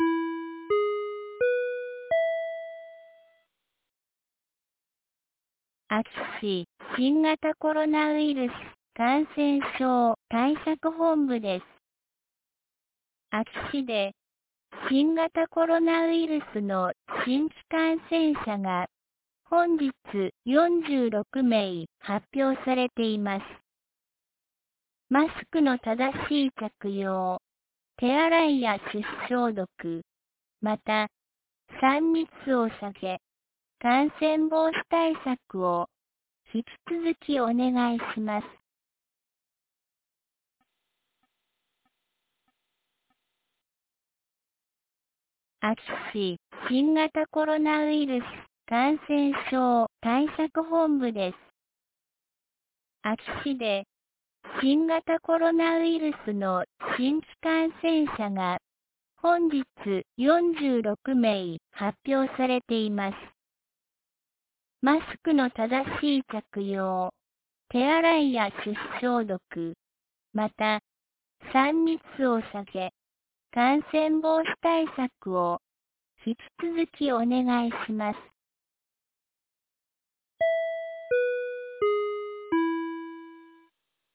2022年08月09日 17時06分に、安芸市より全地区へ放送がありました。